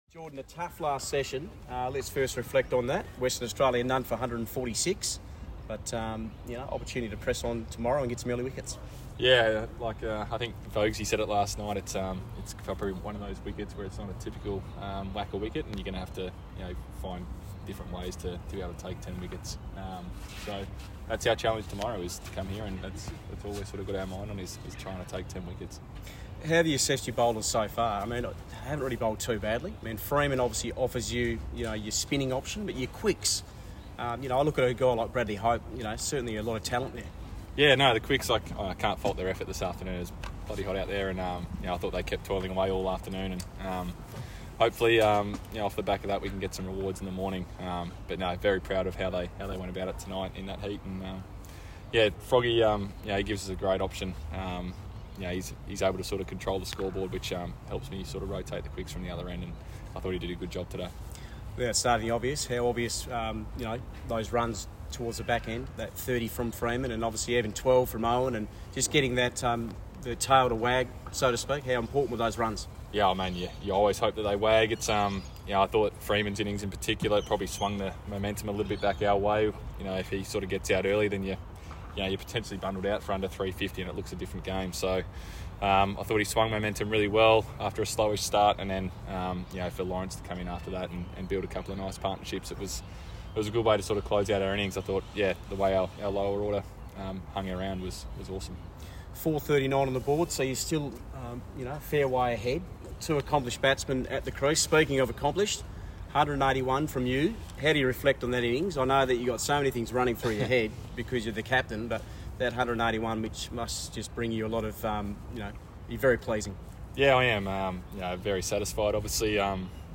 Tasmania captain Jordan Silk, speaking after day two at the WACA. Silk tallied a career-high First Class score of 181.